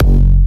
Tremor Kick.wav